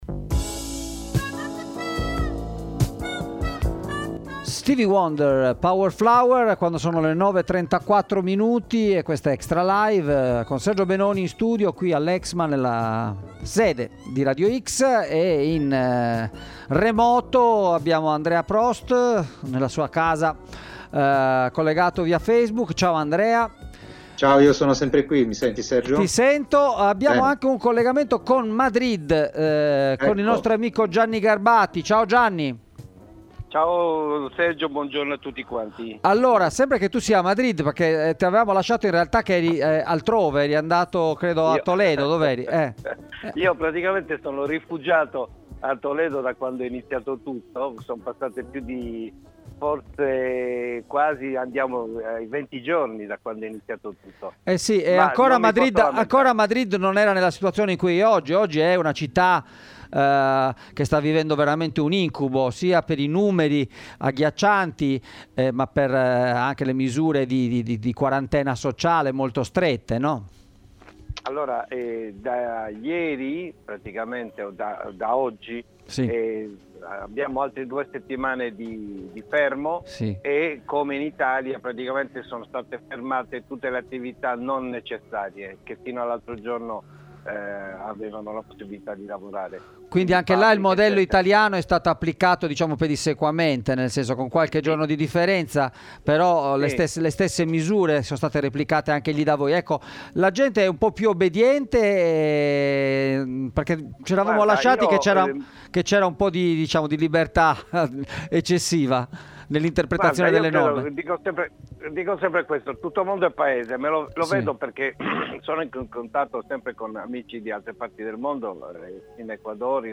In diretta dalla Spagna